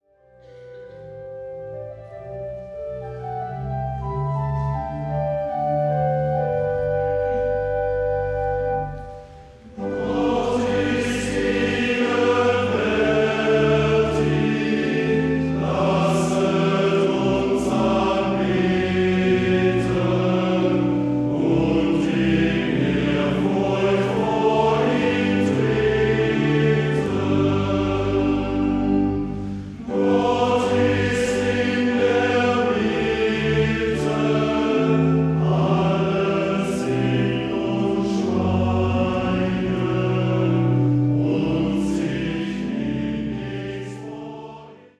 • kurzweilige Zusammenstellung verschiedener Live-Aufnahmen